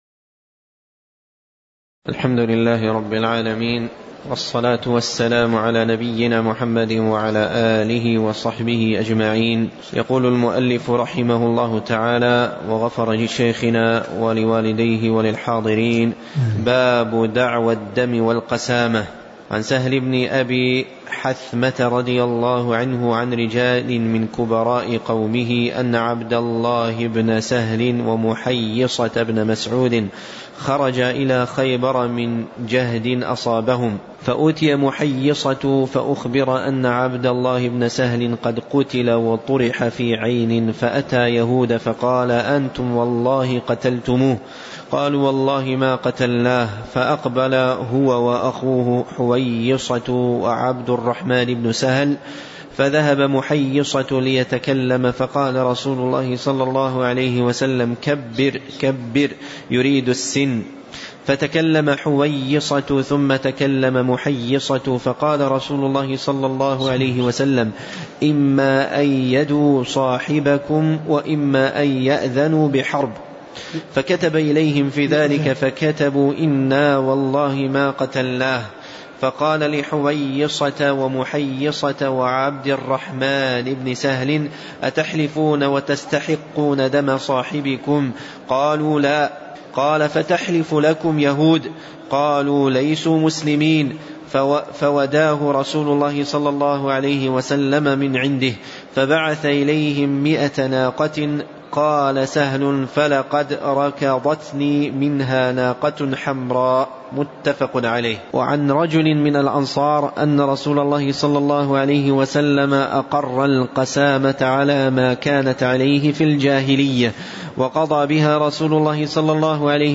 تاريخ النشر ١٧ رجب ١٤٣٩ هـ المكان: المسجد النبوي الشيخ